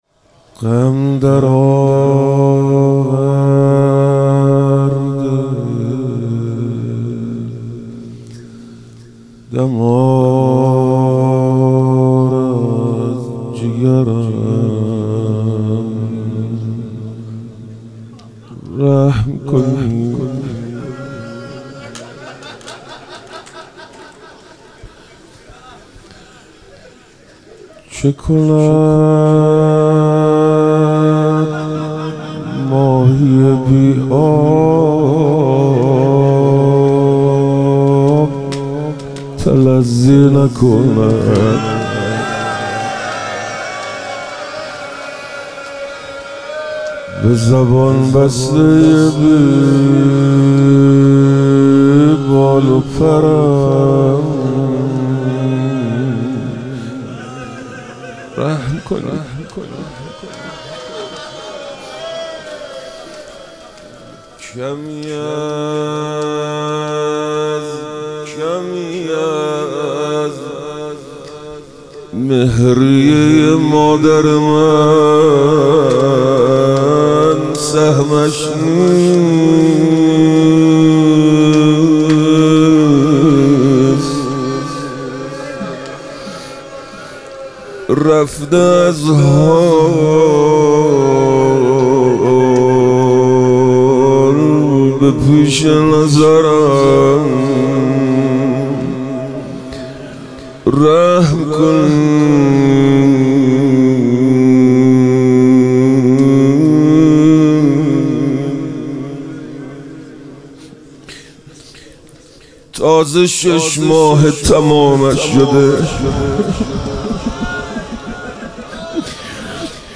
محرم الحرام - روضه